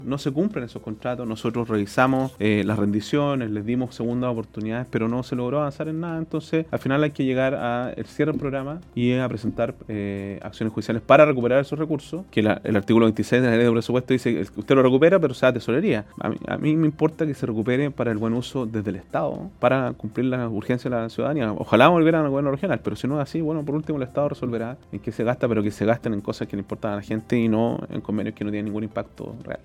En conversación con La Radio, el gobernador regional del Bío Bío, Sergio Giacaman, abordó los primeros seis meses de su administración, donde ha entregado nuevos antecedentes de fundaciones investigadas por el Ministerio Público.
cuna-giacaman-fundaciones.mp3